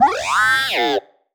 sci-fi_driod_robot_emote_neg_01.wav